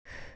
br11_Short_exhale.wav